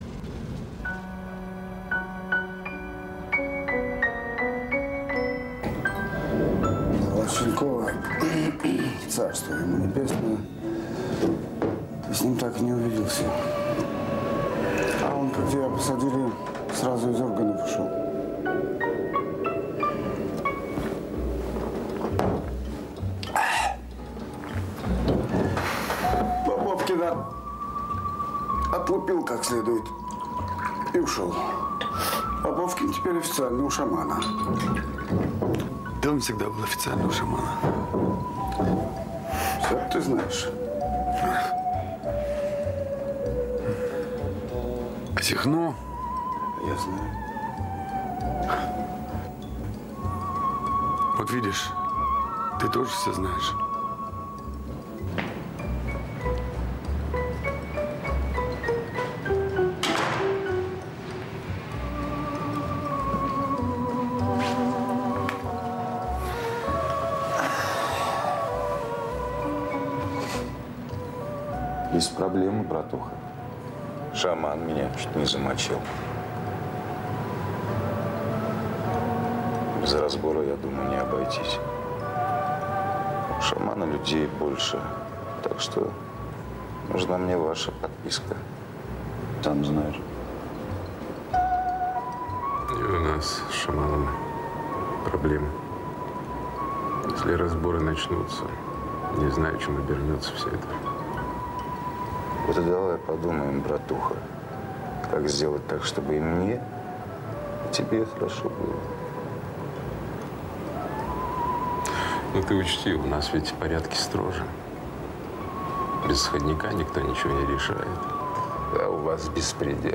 инструментальных композиций